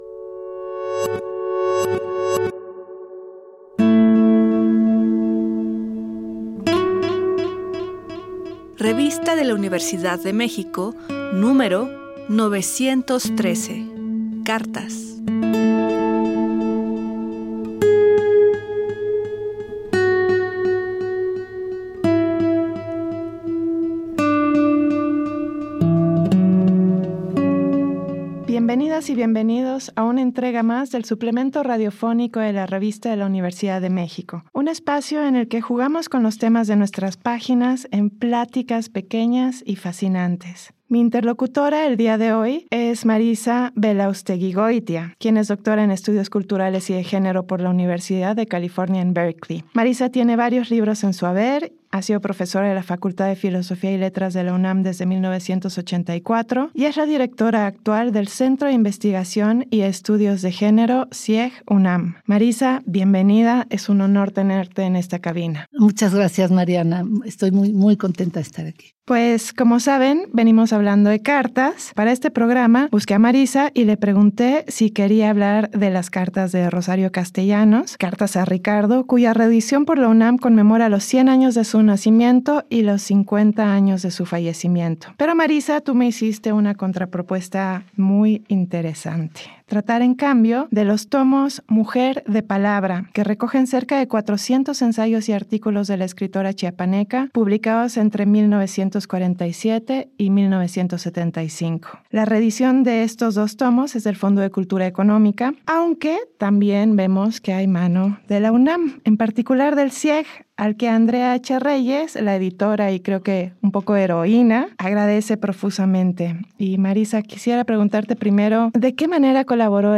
Fue transmitido el jueves 17 de octubre de 2024 por el 96.1 FM.